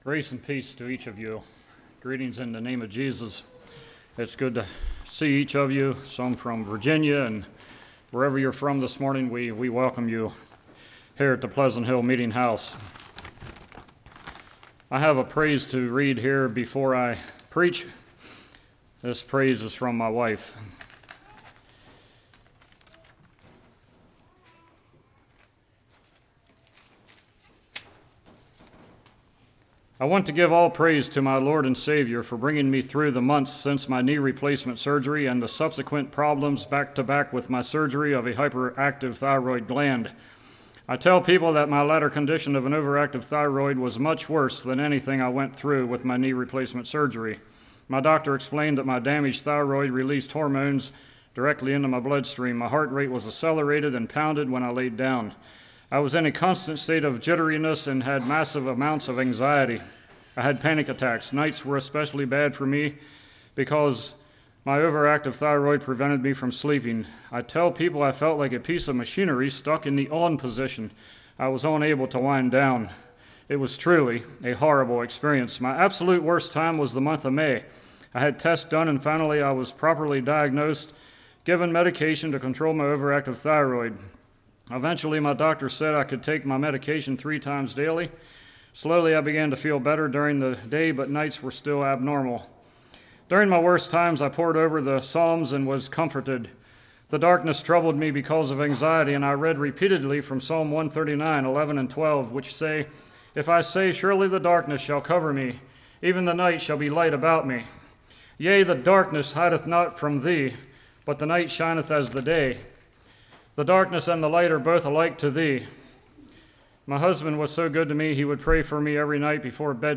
1 Thessalonians 1:1-10 Service Type: Morning Saved without power?